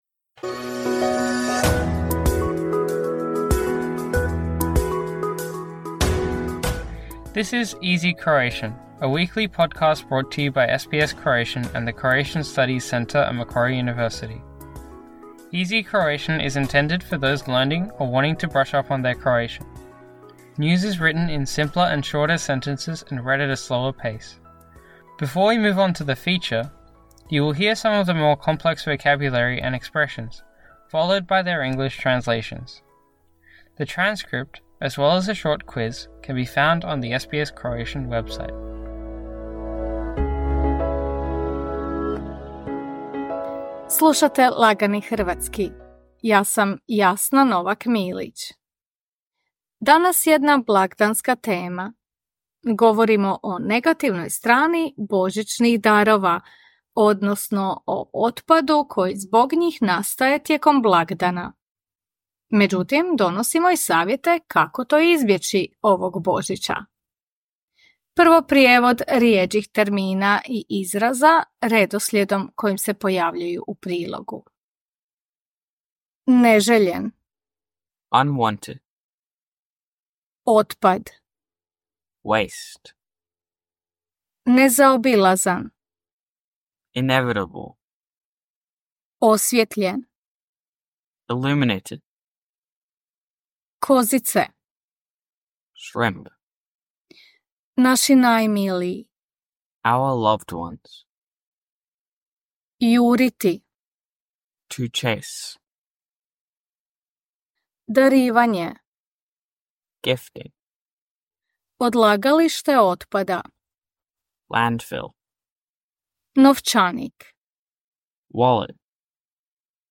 “Easy Croatian” is intended for those learning or wanting to brush up on their Croatian. News is written in simpler and shorter sentences and read at a slower pace. Before we move on to the feature, you will hear some of the more complex vocabulary and expressions, followed by their English translations.